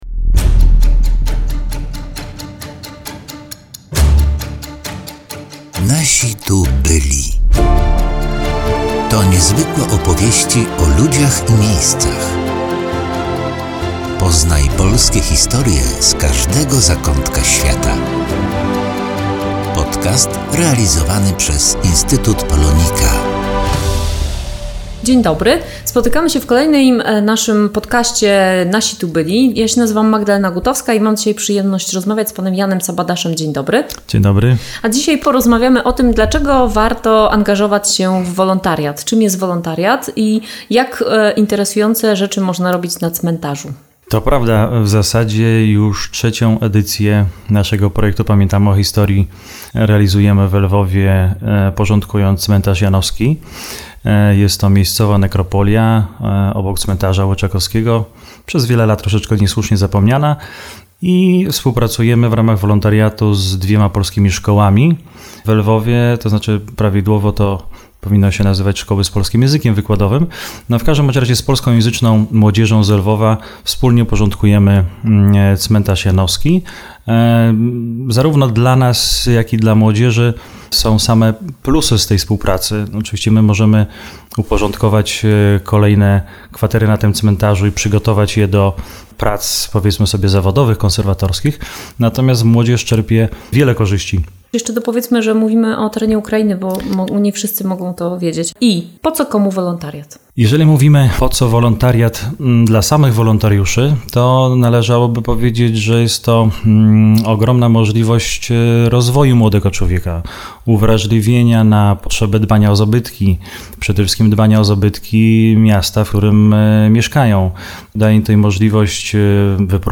W trakcie rozmowy dowiemy się na czym polegają prace realizowane podczas obozów wolontariackich i jak wyglądają zajęcia przygotowywane przez profesjonalnych konserwatorów.